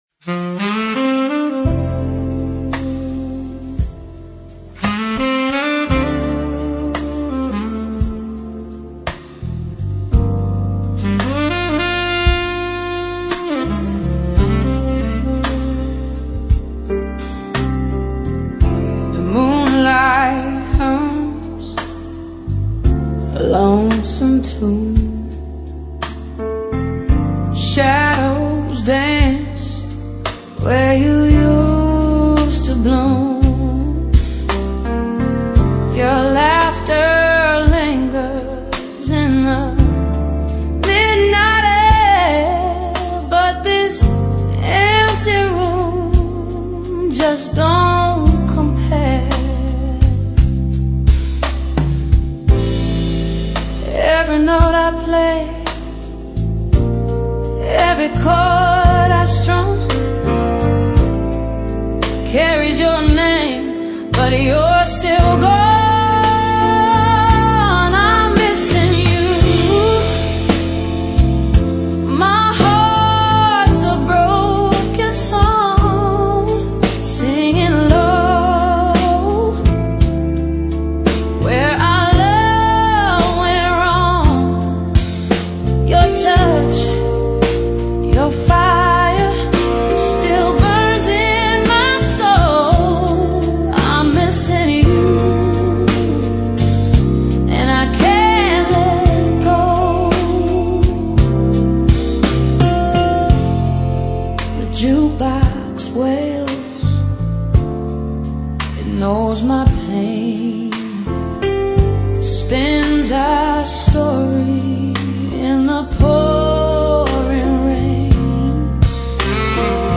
Blues Jazz Soul Fusion